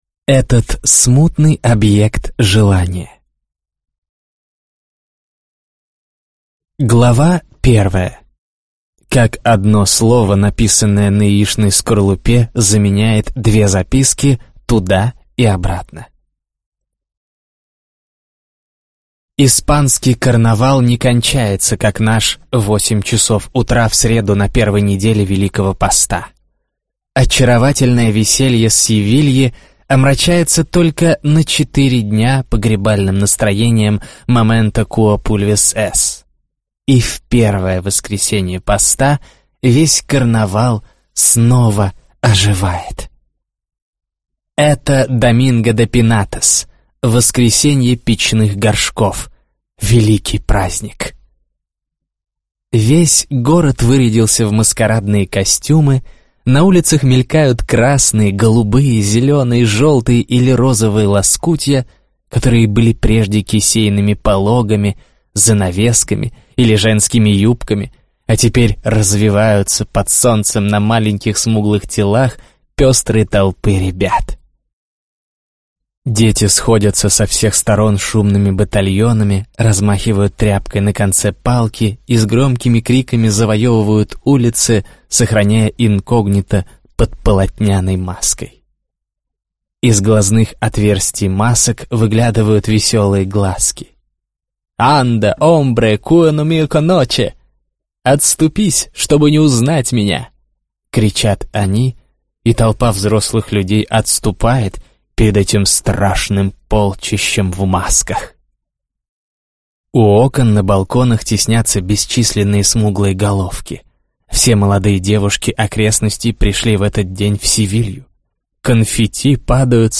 Аудиокнига Этот смутный объект желания | Библиотека аудиокниг